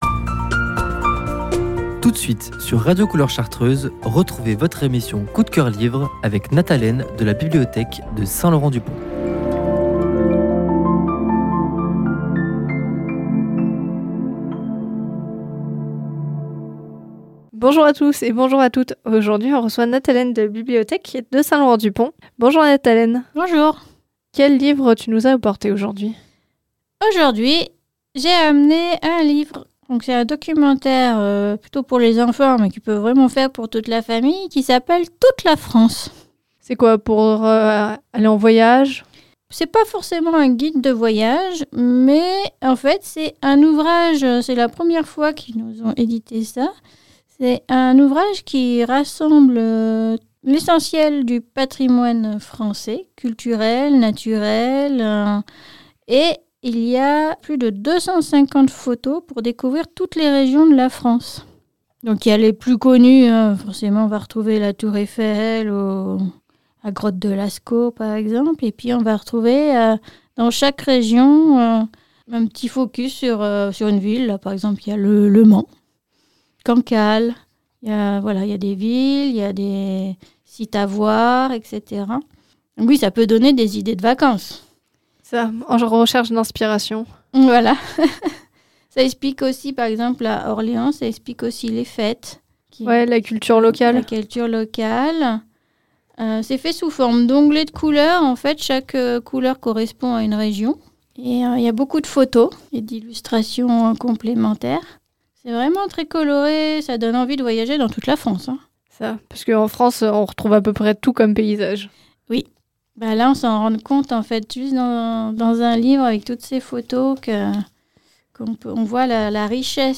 La chronique coup de cœur livre